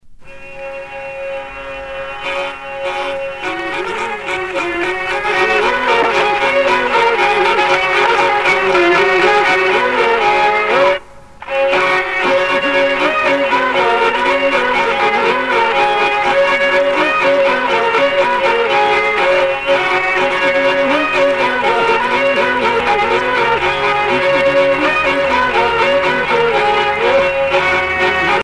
danse : branle : avant-deux
Sonneurs de vielle traditionnels en Bretagne